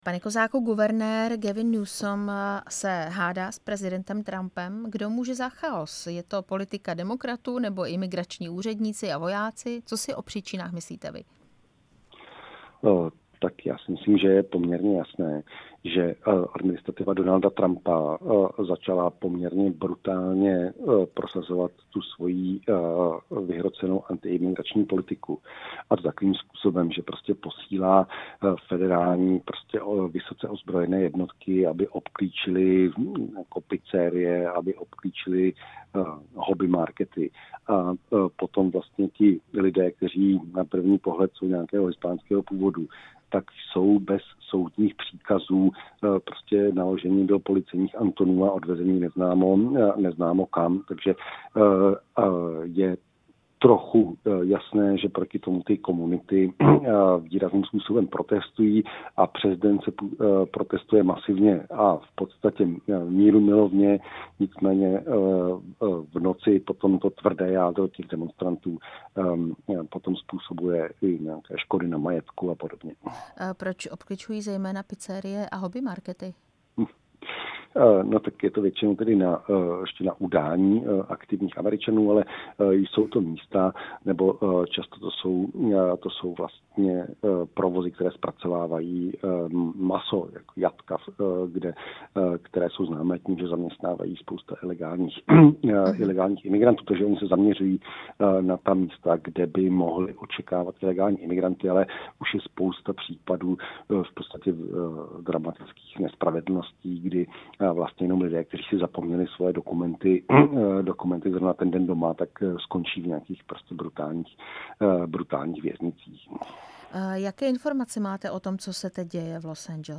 Rozhovor s amerikanistou